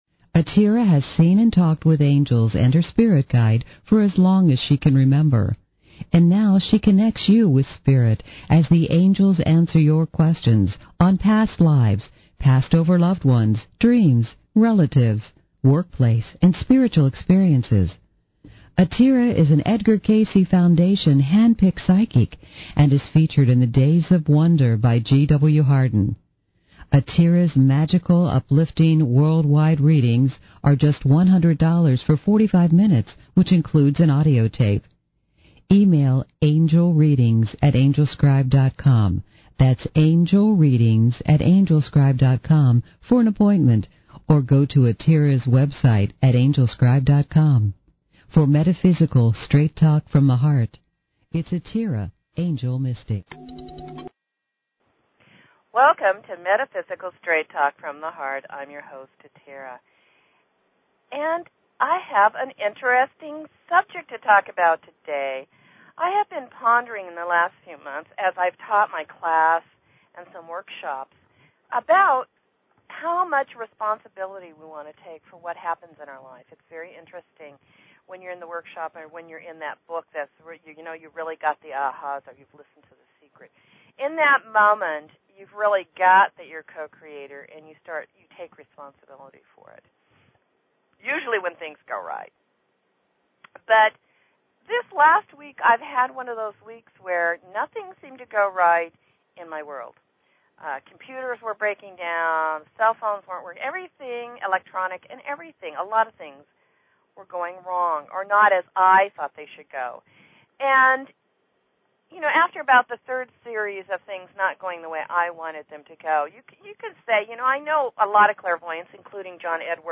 Talk Show Episode, Audio Podcast, Metaphysical_Straight_Talk and Courtesy of BBS Radio on , show guests , about , categorized as